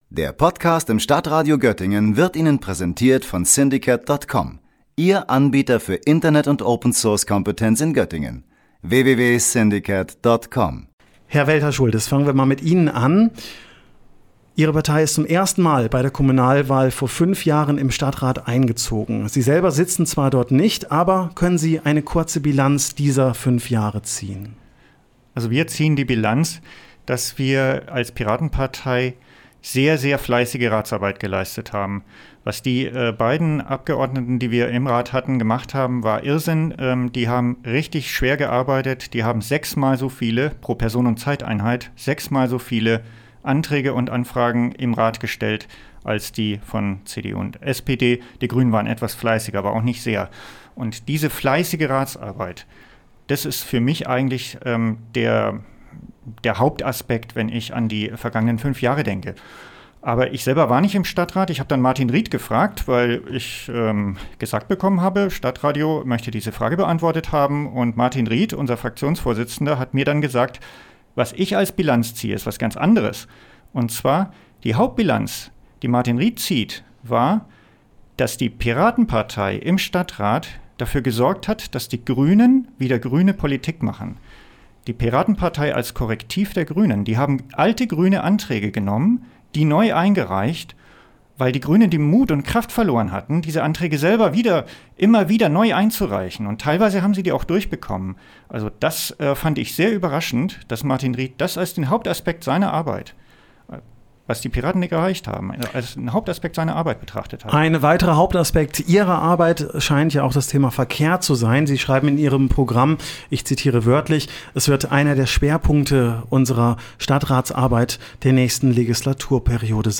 Denn am Sonntag findet auch in Göttingen die Kommunalwahl statt. Wir sprechen jeden Tag mit einem Kandidaten der jeweiligen Parteien, die zur Wahl antreten.